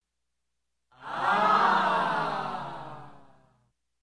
Звуки восхищения
На этой странице собраны звуки восхищения — яркие эмоциональные возгласы, восторженные восклицания и другие проявления удивления.
Wow толпы